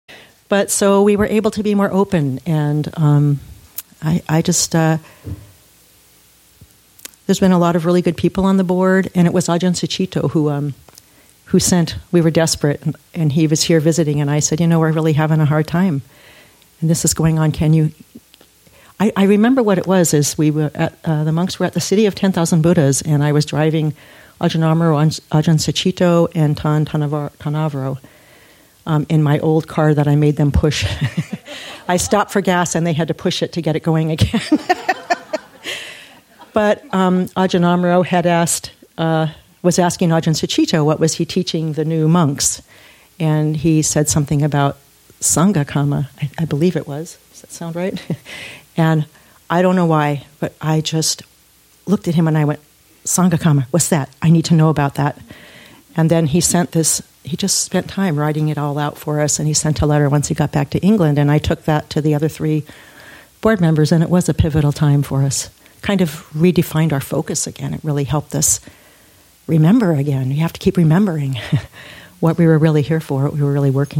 Story